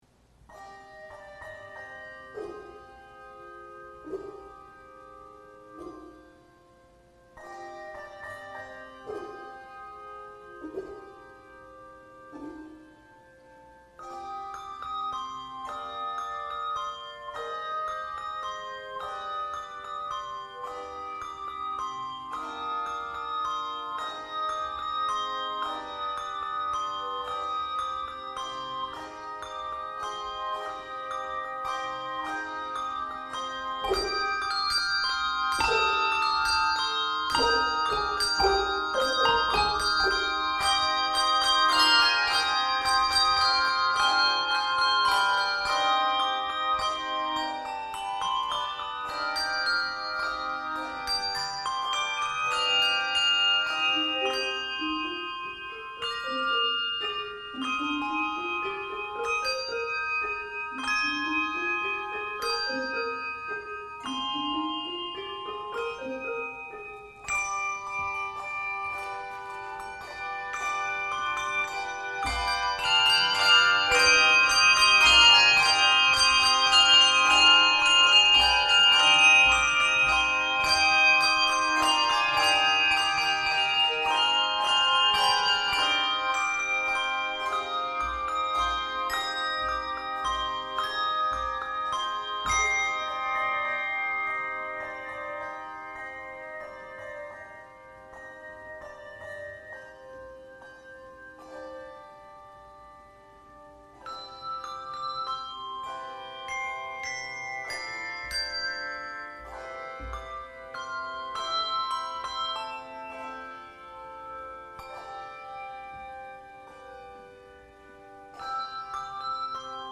“Christmas Eve 7PM”
Audio Sermons